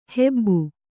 ♪ hebbu